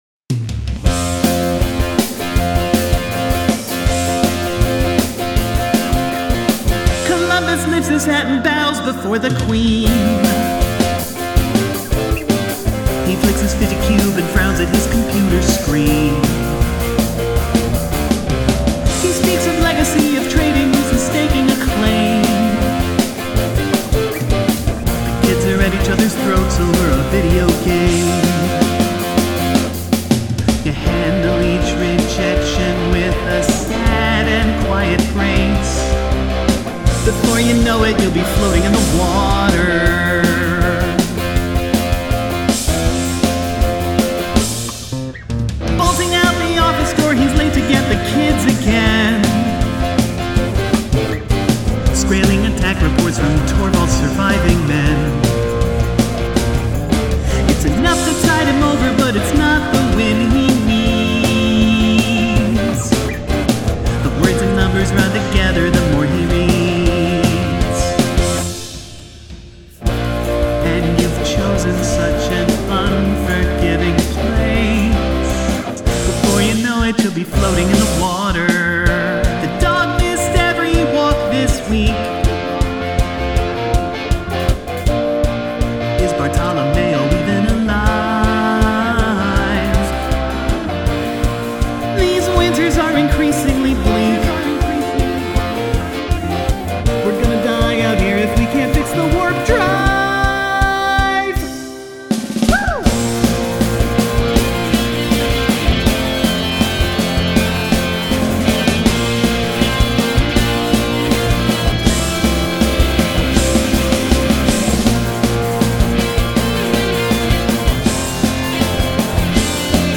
These don't appear in the recording directly, but I sent them to a reverb and muted the source audio, so when I sing a verse line, you're hearing reverb from another timeline.
Nice dynamics.